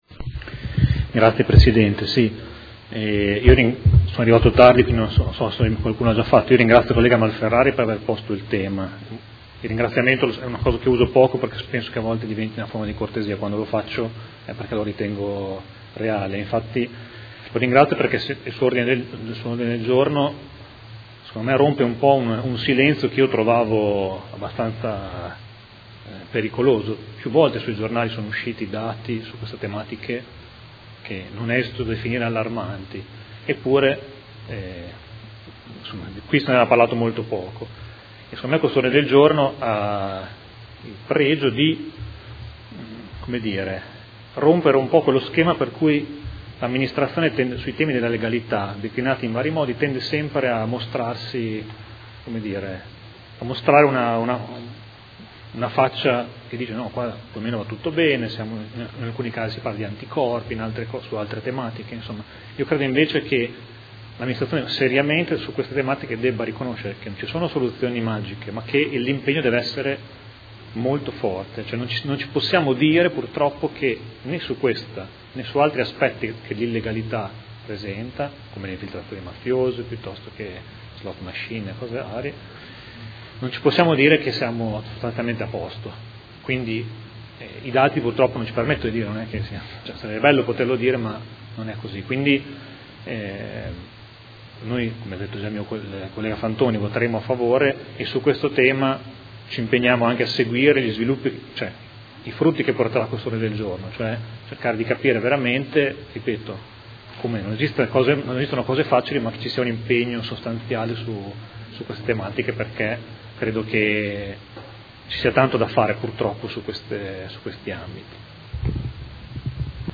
Seduta del 15/06/2017. Dibattito su Ordine del Giorno presentato dal Gruppo Art.1-MDP avente per oggetto: Affrontare con urgenza i gravi problemi di irregolarità del lavoro che emergono dai controlli ispettivi ed Emendamenti